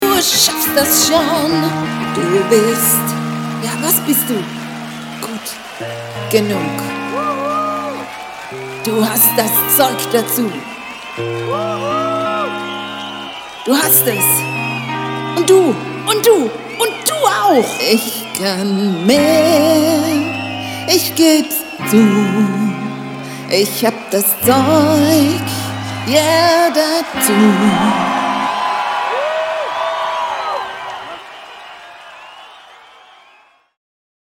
Live Ausschnitt vor der Bühne mit gewünschter Animation
Deutsche Songs